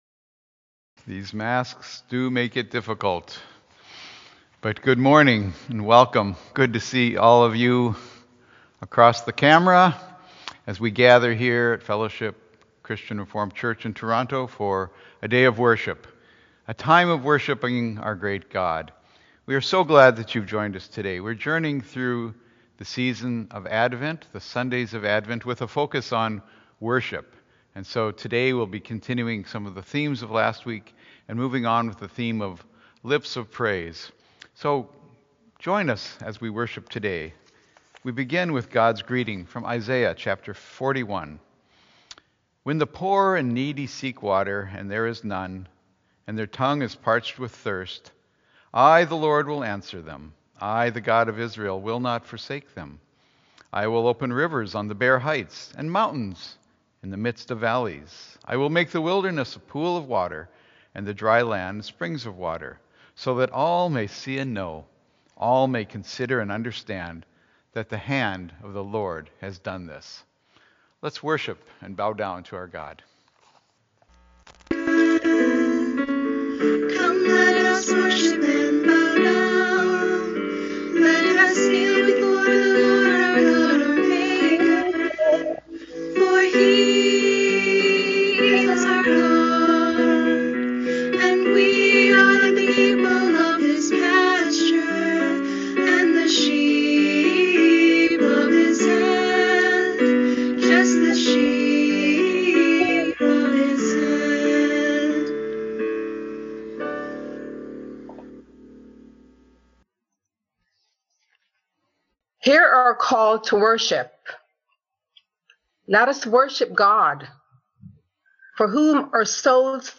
Fellowship Church is pleased to offer this live service at 10AM.